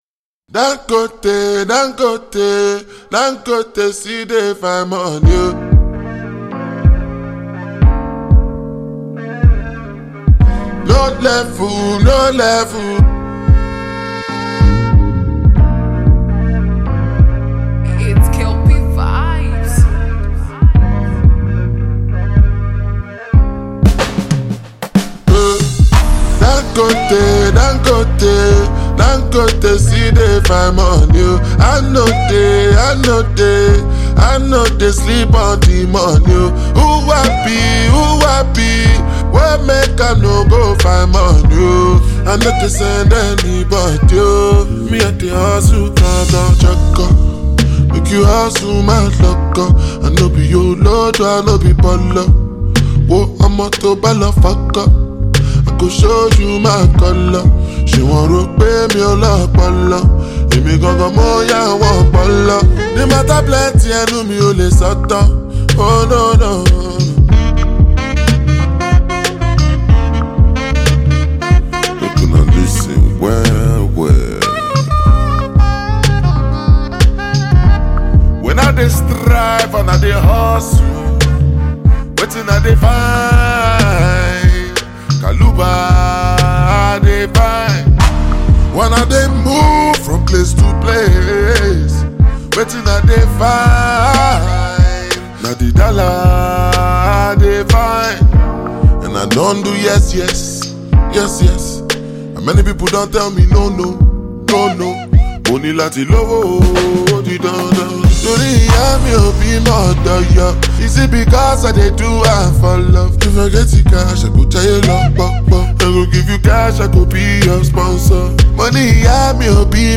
mid tempo Afro-beat track